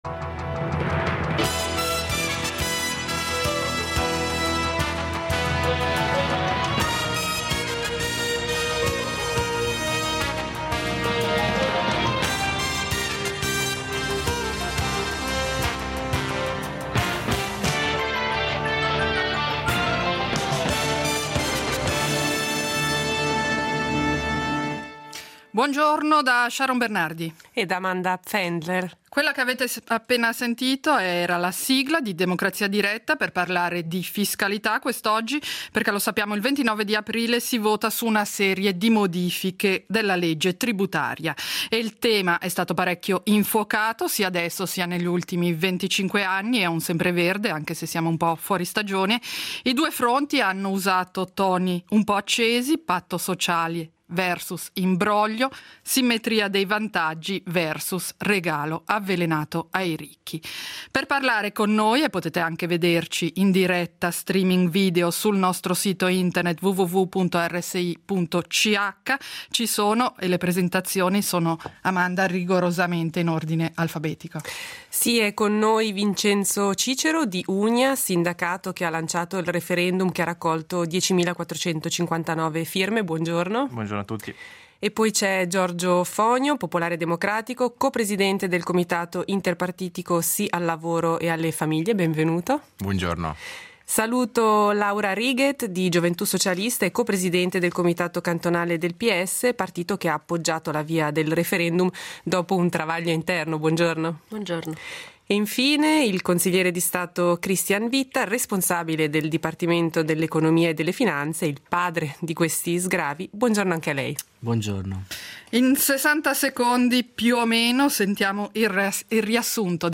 Dibattito sulla Riforma fiscosociale, parte della quale – quella tributaria – è in votazione il prossimo 29 aprile.